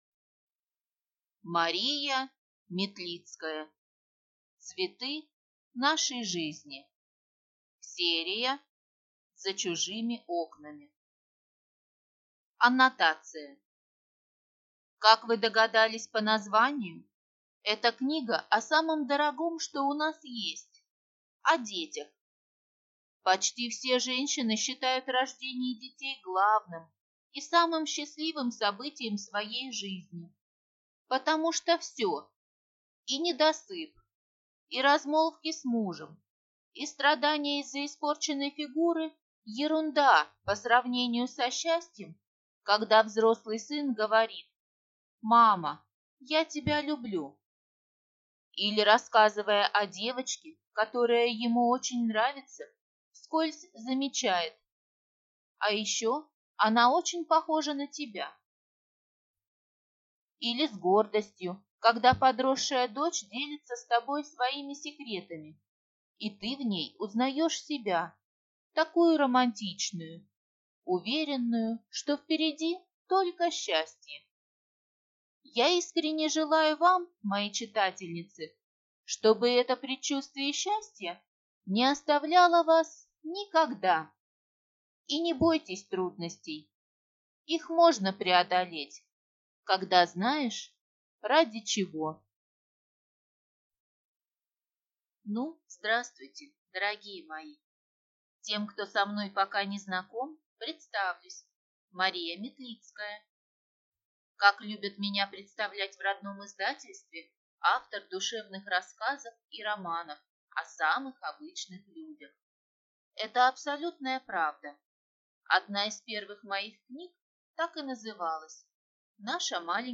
Аудиокнига Цветы нашей жизни | Библиотека аудиокниг